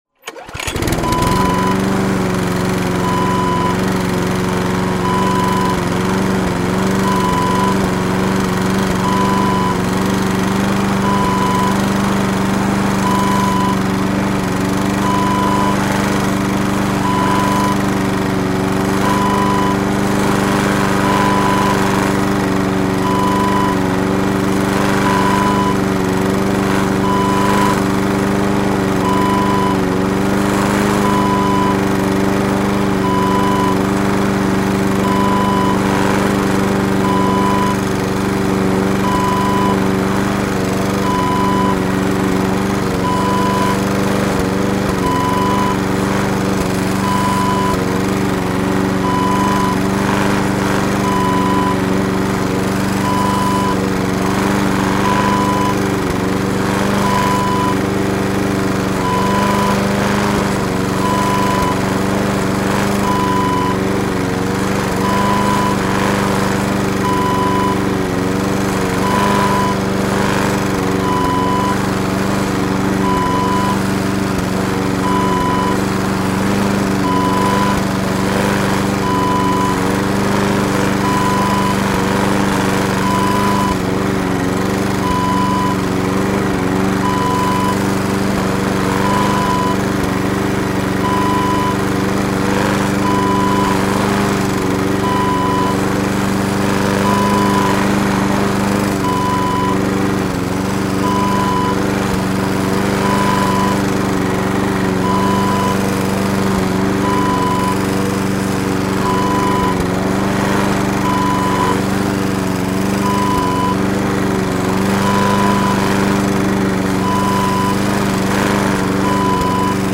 Lawn mower sound effect .wav #2
Description: The sound of a lawn mower starting up, cutting grass and shutting off
Properties: 48.000 kHz 24-bit Stereo
A beep sound is embedded in the audio preview file but it is not present in the high resolution downloadable wav file.
lawn-mower-preview-2.mp3